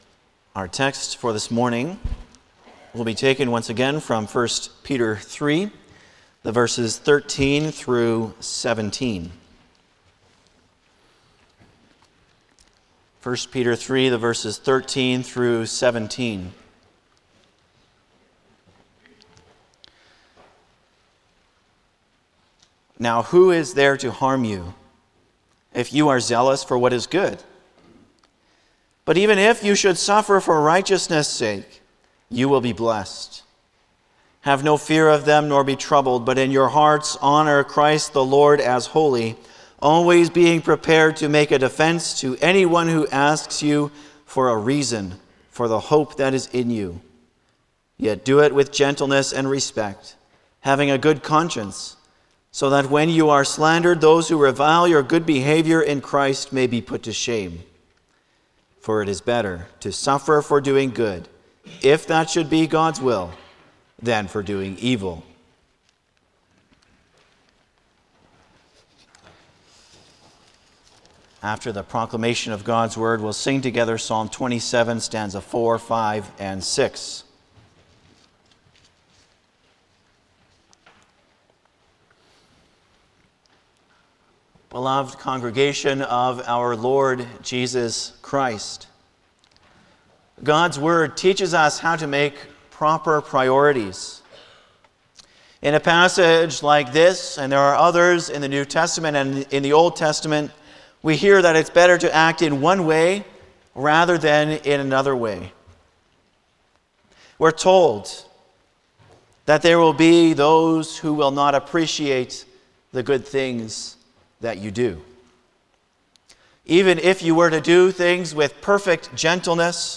Passage: 1 Peter 3:13-17 Service Type: Sunday morning